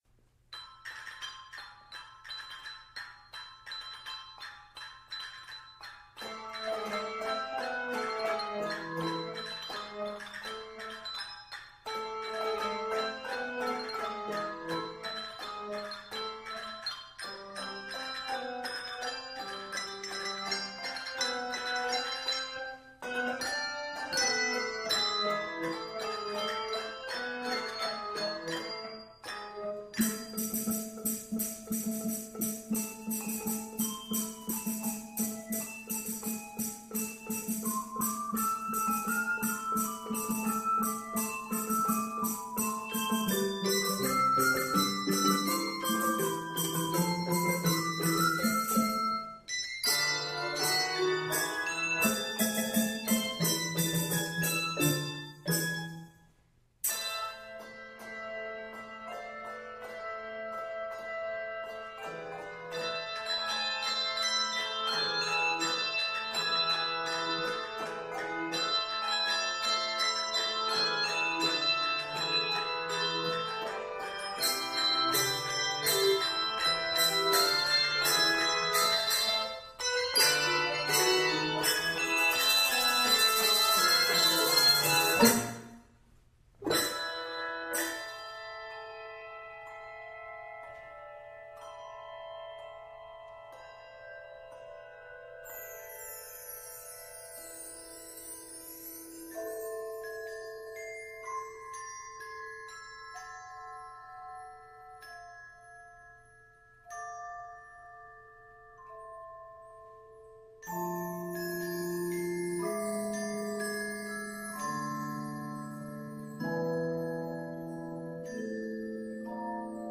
It is set in a minor.